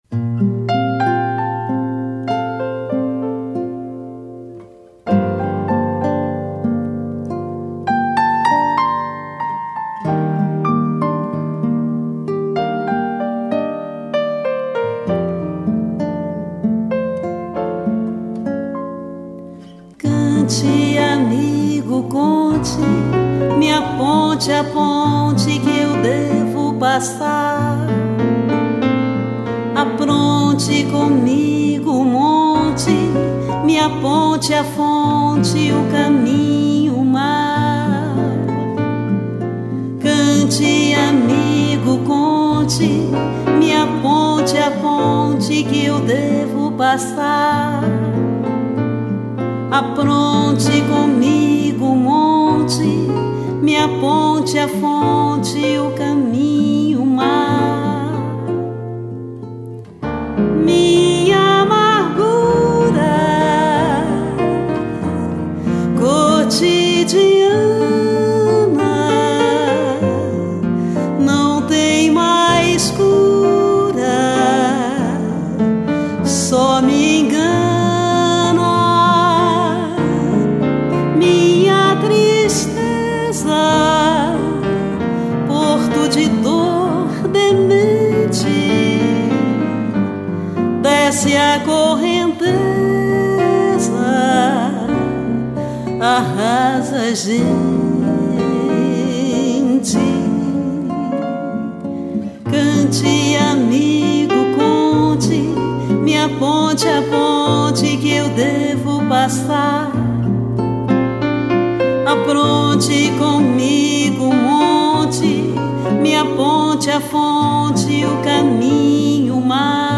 Voz
Violão
Teclados